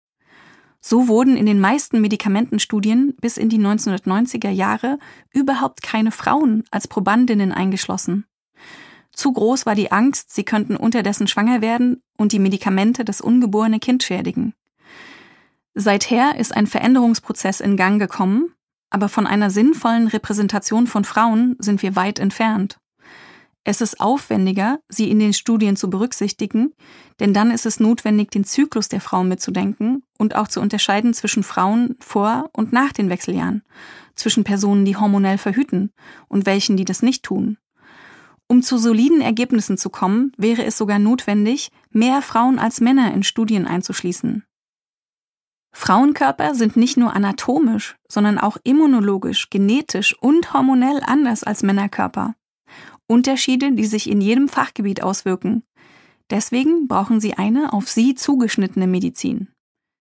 Produkttyp: Hörbuch-Download
Mit einem Vorwort gelesen von der Autorin.